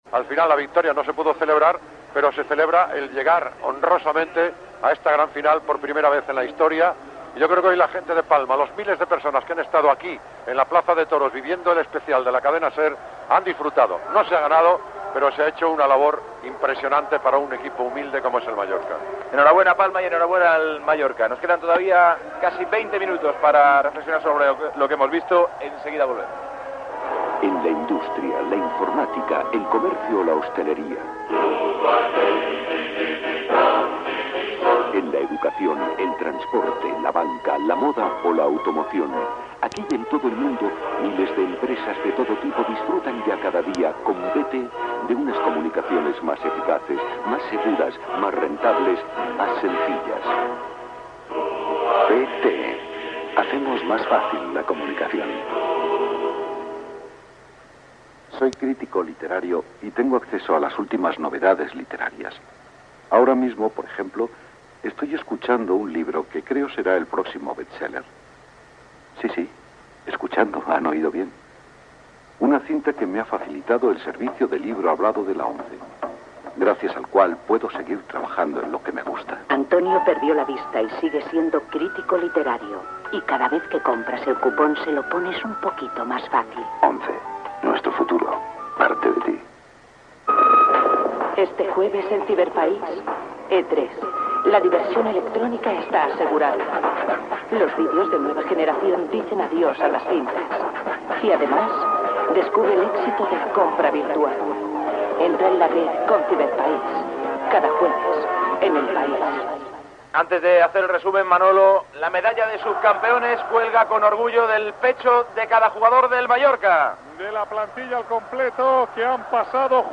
Transmissió de la final de la Recopa d'Europa de Futbol masculí. Postpartit en el qual el Lazio havia guanyat al Mallorca per 2-1
Esportiu